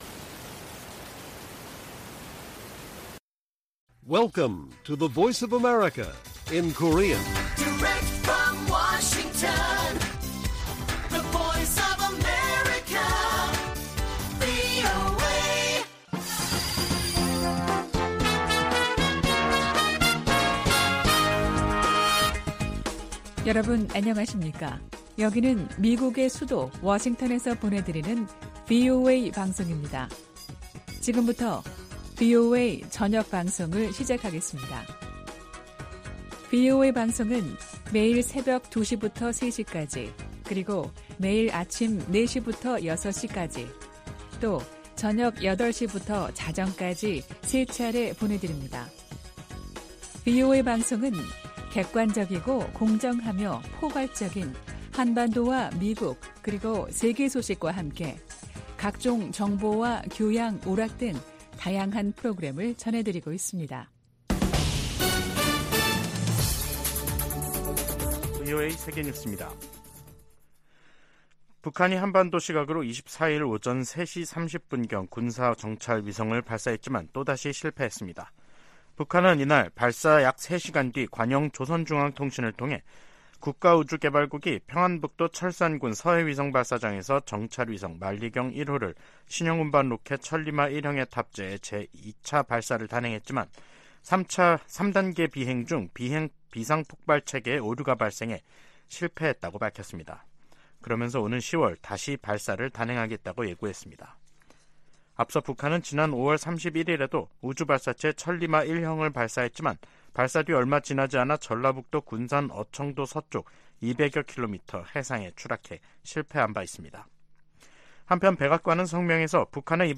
VOA 한국어 간판 뉴스 프로그램 '뉴스 투데이', 2023년 8월 24일 1부 방송입니다. 북한이 2차 군사 정찰위성 발사를 시도했지만 또 실패했습니다. 백악관은 북한 위성 발사가 안보리 결의에 위배된다고 규탄하며 필요한 모든 조치를 취할 것이라고 밝혔습니다. 미 국방부가 생물무기를 계속 개발하는 국가 중 하나로 북한을 지목했습니다.